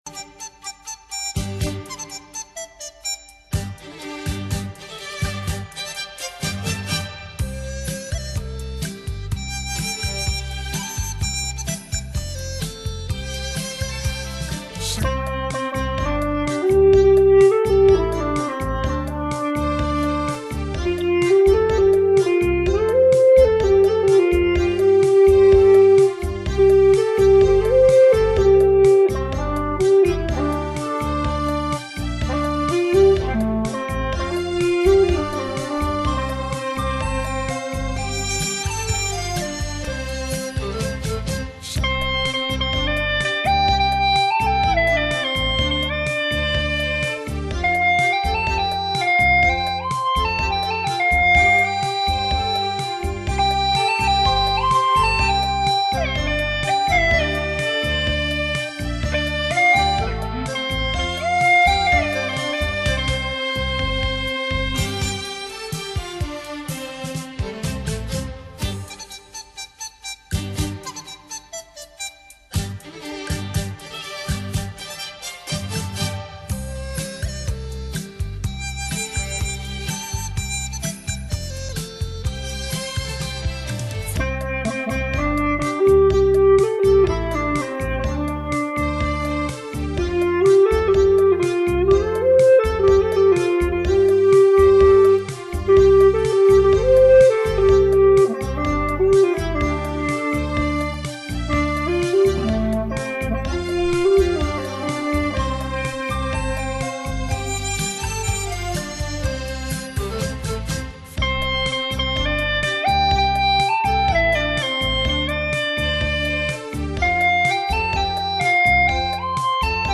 大F葫芦太大了，感觉手指不够长，摆弄了半天试吹一曲，用小葫芦配录，很不成熟，请老师们多多指导，谢谢~
这声音多浑厚啊，大哥哥一样，好好学习慢慢练吧，我来给你加油了！